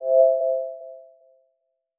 startup.wav